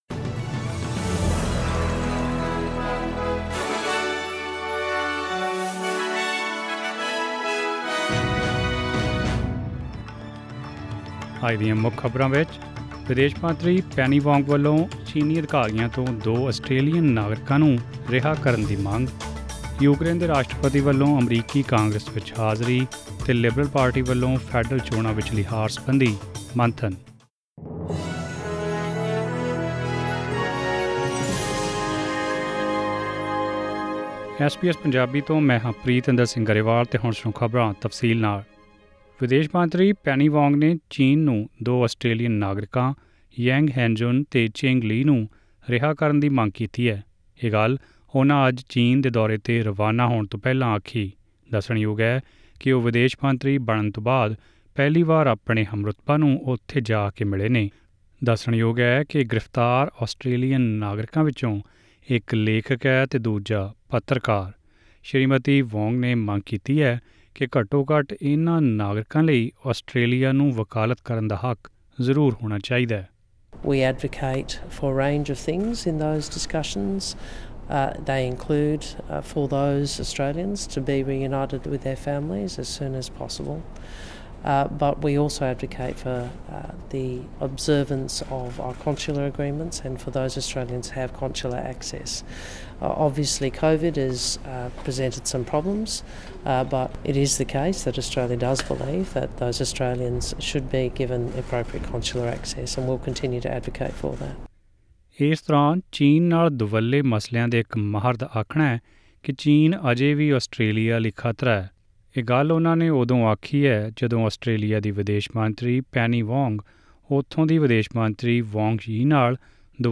Presenting the major national and international news stories of today; sports, currency exchange details and the weather forecast for tomorrow. Click on the audio button to listen to this news bulletin in Punjabi....